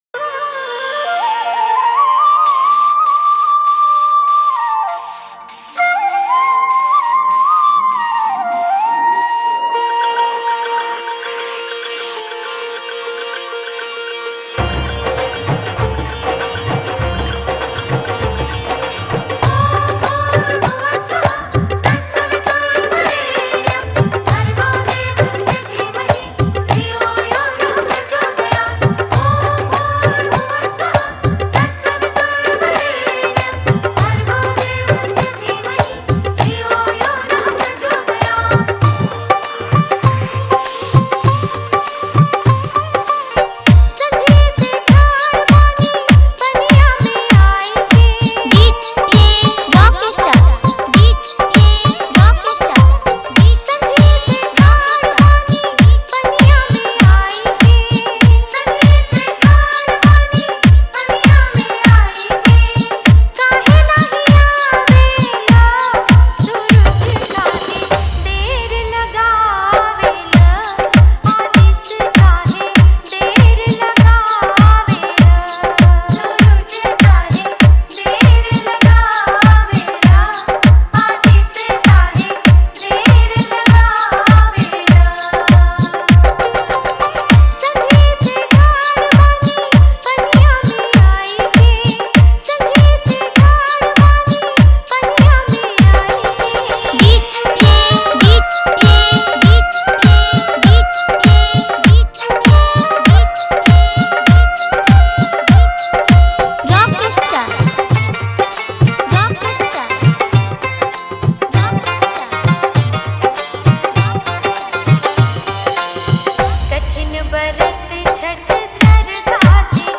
Category:  Chhath Puja Dj Songs 2022